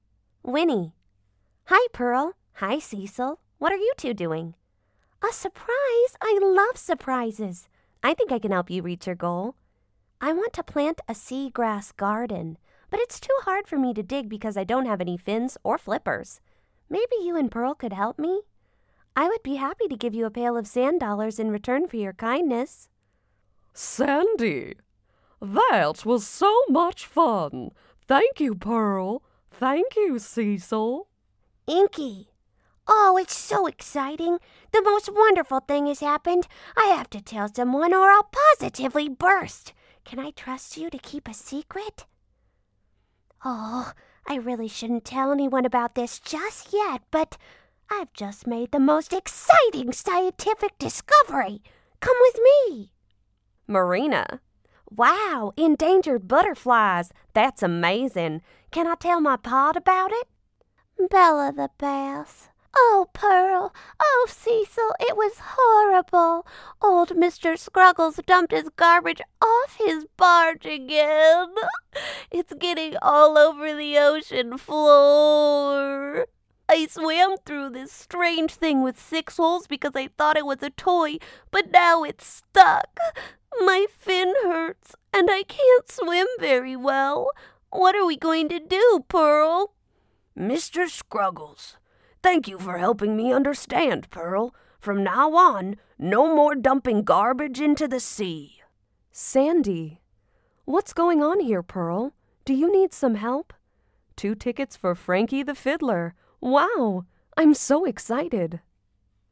Animated Voice Compilation
English - General American, Midwestern American, RP, Cockney, Central Scottish, South Midland/Southern Appalachian, Polish, Bronx
Cartoon / Animation
Animation Voices Complilation .mp3